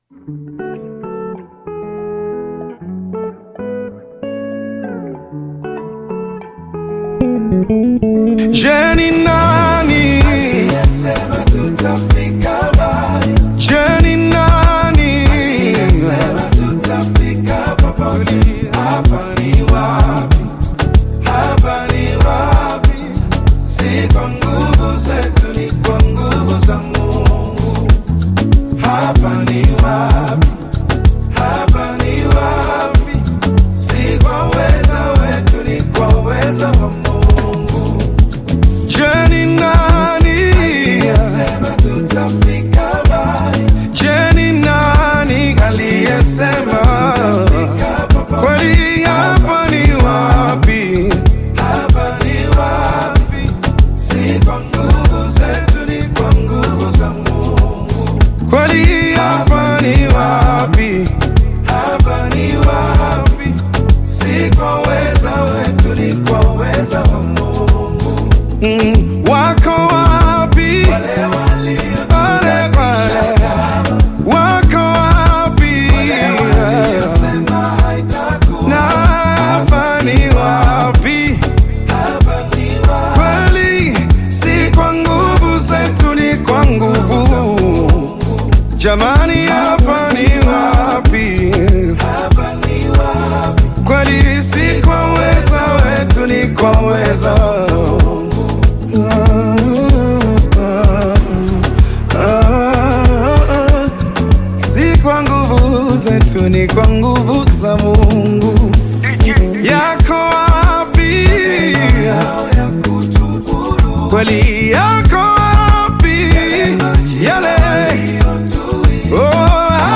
Gospel music track
Tanzanian Gospel artist and singer